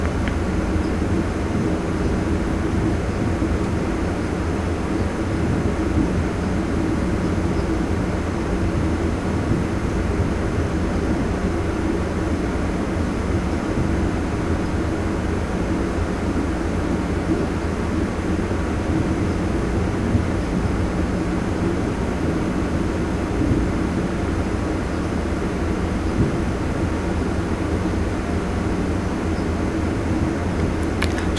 Using Audacity's equalizer, I removed 130Hz and below and it helped, but there is still quite a bit of white noise from the room.
View attachment Noise Profile with 130 Hz notch.mp3